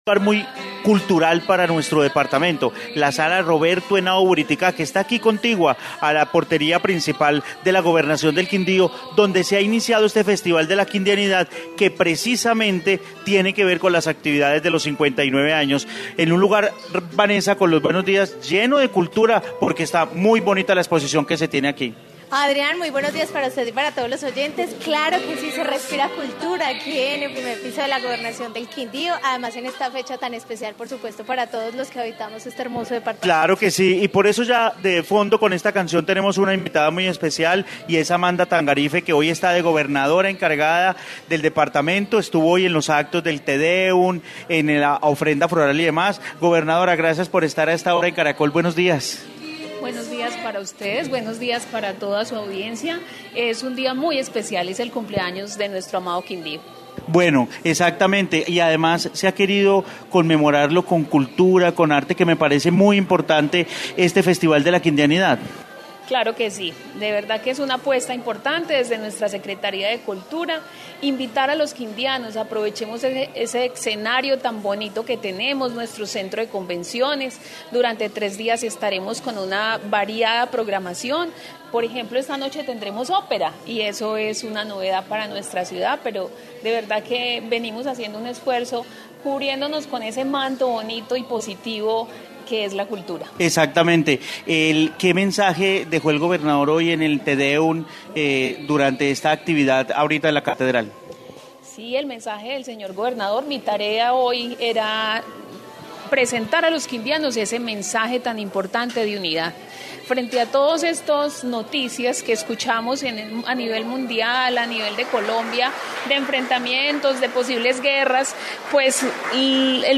Amanda Tangarife, gobernadora encargada del Quindío
En efecto Caracol Radio realizó la transmisión especial desde la sala de exposiciones Roberto Henao Buriticá que también rinde homenaje al Quindío, precisamente la gobernadora encargada del departamento Amanda Tangarife exaltó la importante agenda cultural que se tiene hasta el 3 de julio.